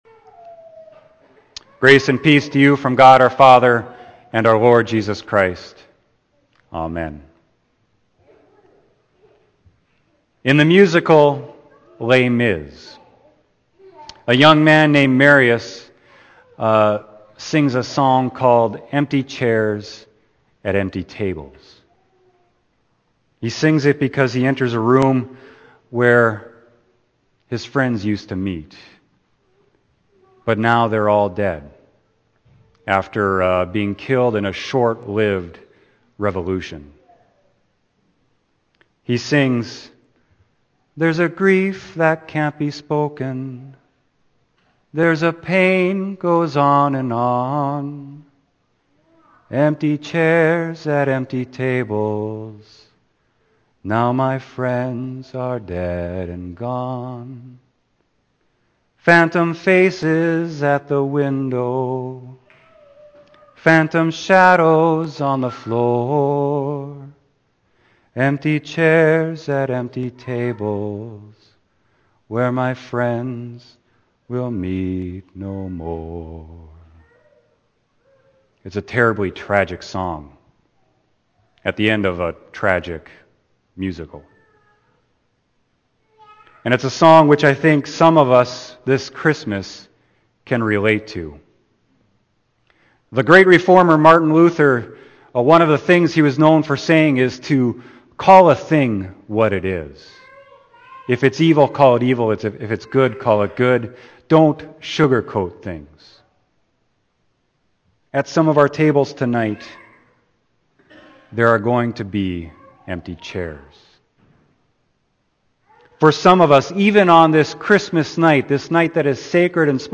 Scripture: Matthew 1:18-25 Sermon: Christmas Eve 2015